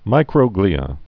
(mīkrō-glēə, -glī-)